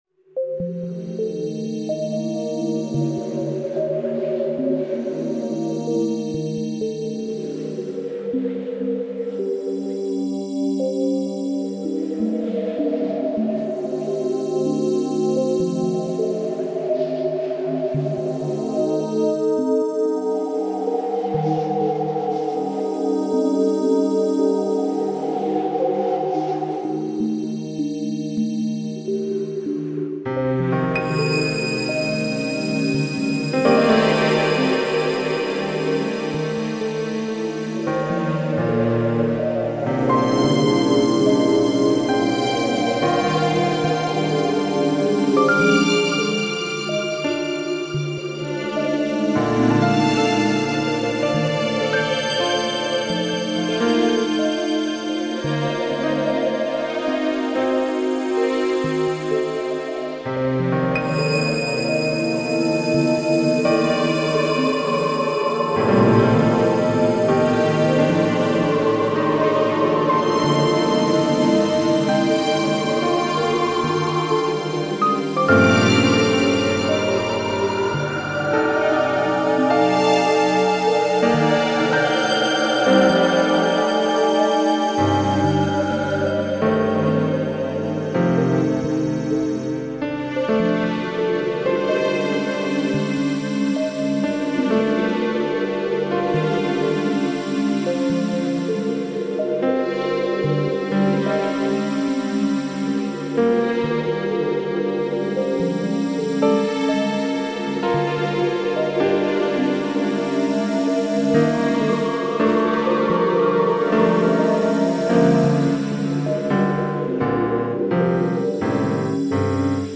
Categorised in: atmospheric